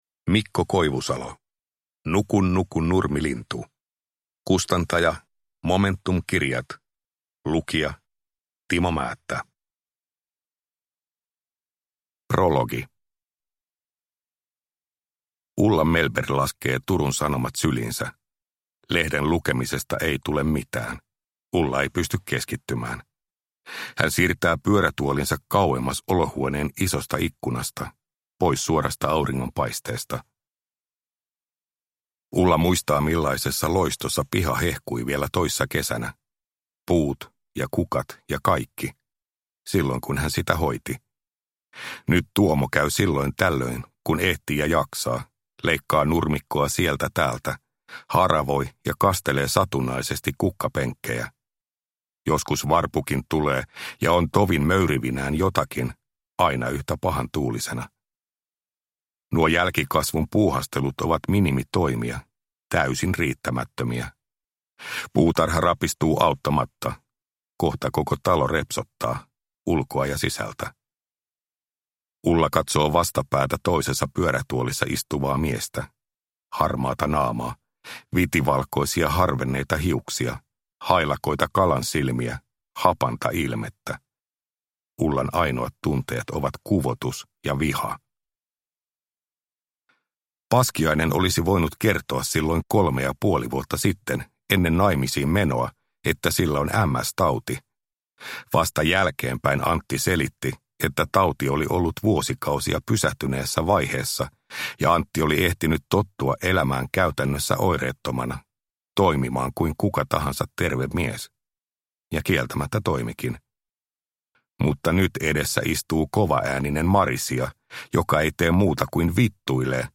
Nuku nuku nurmilintu (ljudbok) av Mikko Koivusalo